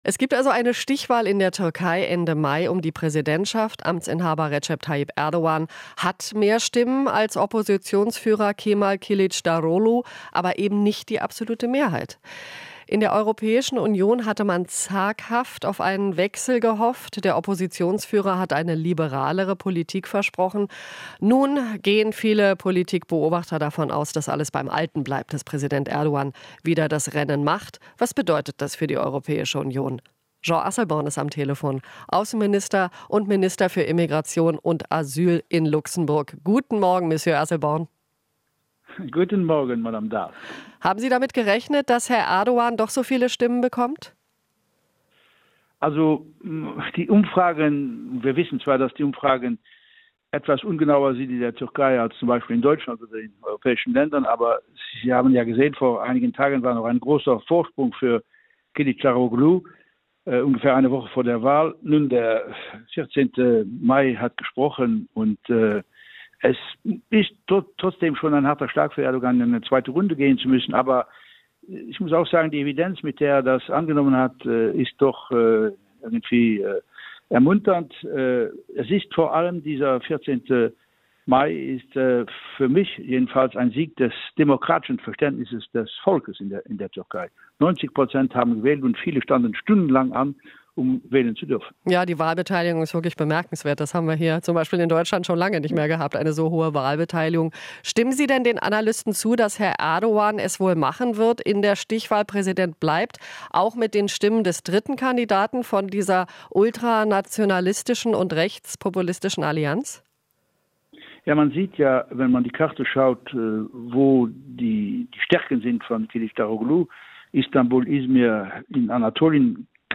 Jean Asselborn, Außenminister von Luxemburg
Interview - Asselborn: Keine Annäherung mit Erdoğan